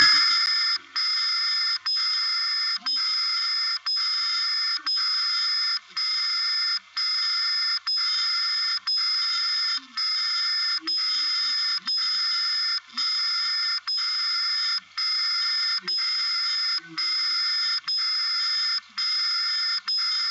German SFTS station located in Mainflingen on 77.5 kHz.